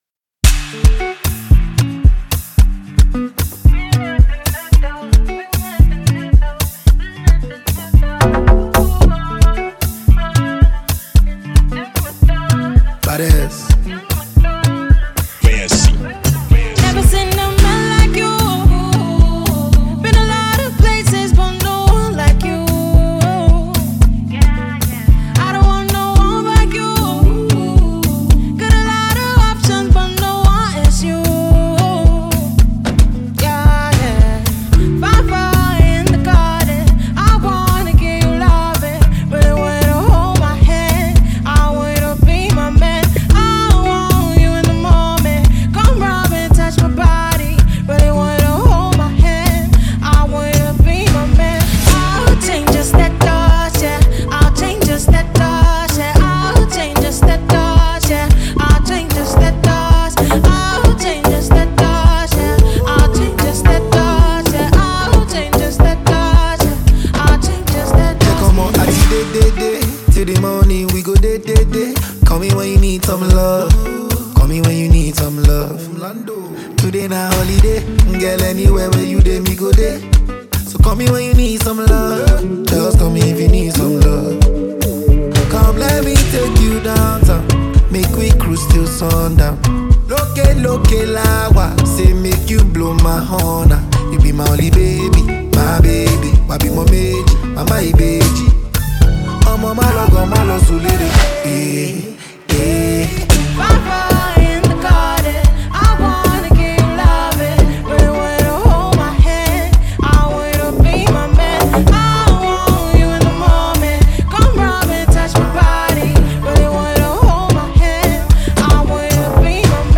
The album mixes Afrobeats, amapiano, and highlife sounds.